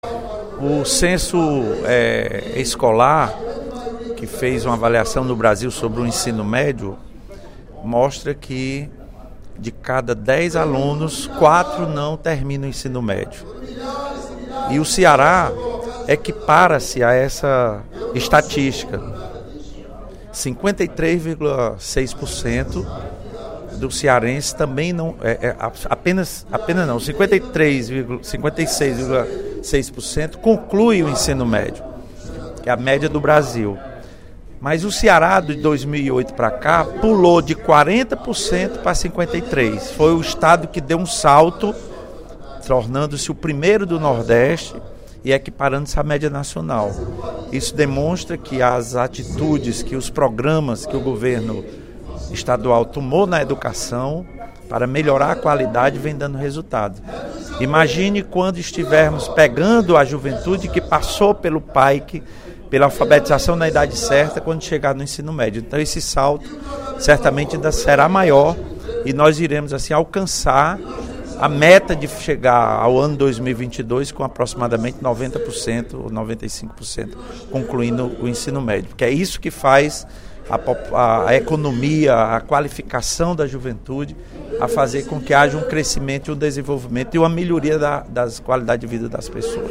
Durante o primeiro expediente da sessão plenária desta terça-feira (09/12), o deputado Lula Morais (PCdoB) ressaltou a situação de jovens cearense do ensino médio, divulgado esta semana pelo Censo Escolar no Brasil.